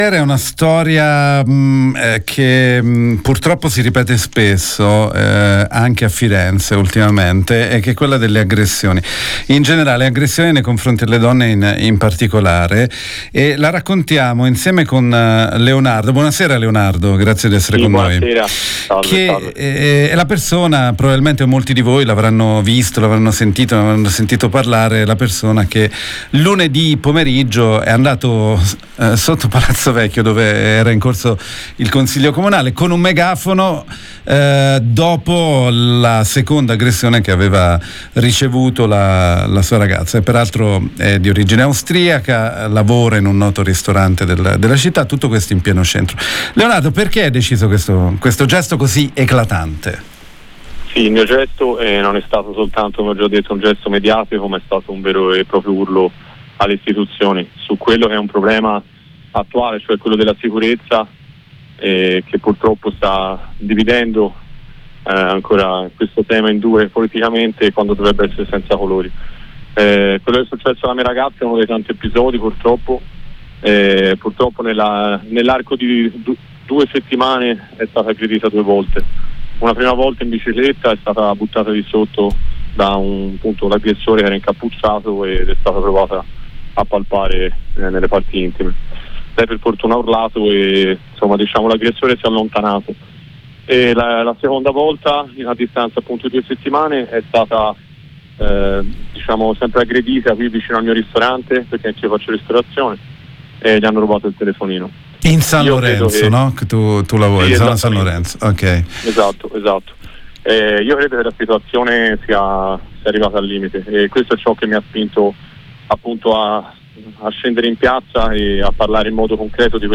Lo abbiamo intervistato